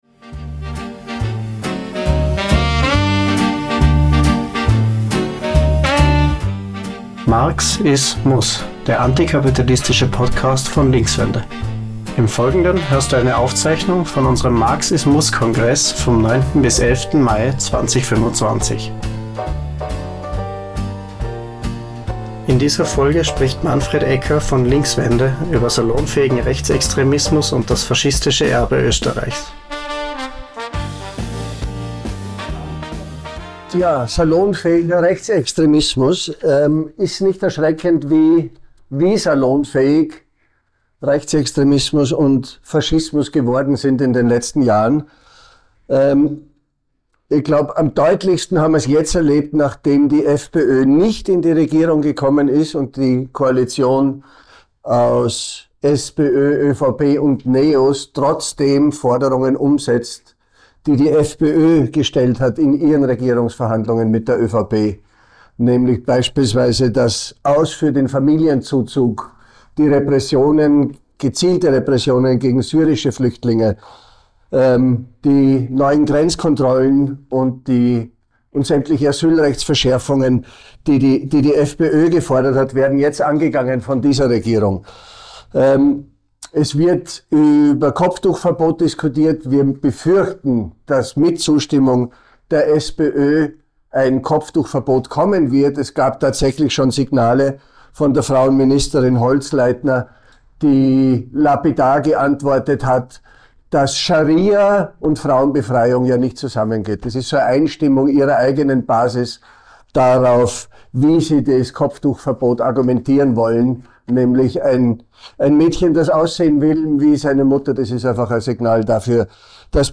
Du hörst eine Aufzeichnung vom MARX IS MUSS Kongress von 09.-11. Mai 2025 in Wien.